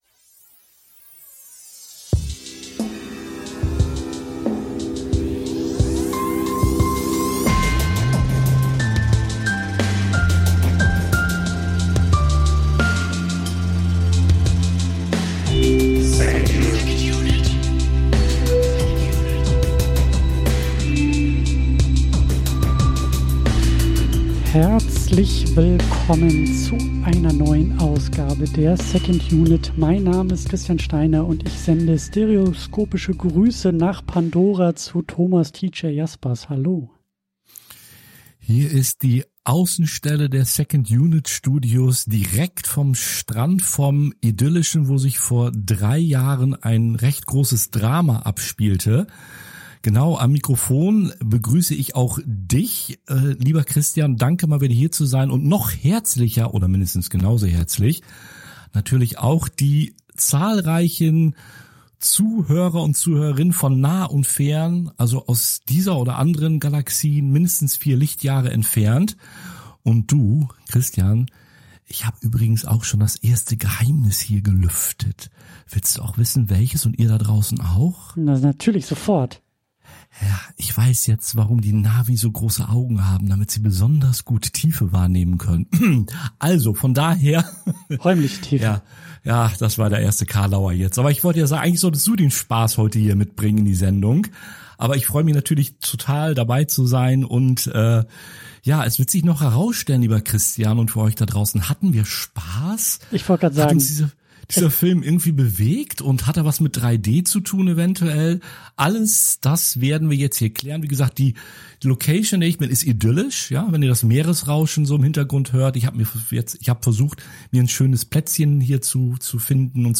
In einer Mischung aus philosophischem Gespräch und filmwissenschaftlichem Seminar entsteht dabei ein ganz eigenes Filmgespräch.